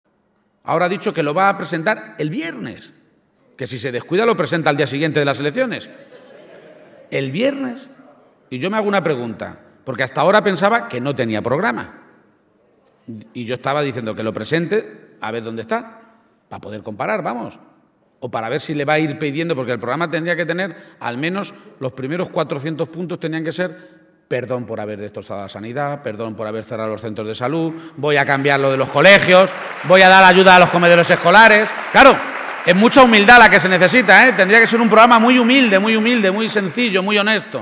El candidato del PSOE a la Presidencia de Castilla-La Mancha, Emiliano García-Page, aseguraba hoy en Talavera de la Reina (Toledo) que “esta tierra no necesita un cambio histórico, ese ya lo hicimos nosotros, sino ir todos a una el día 24 a cambiarla”.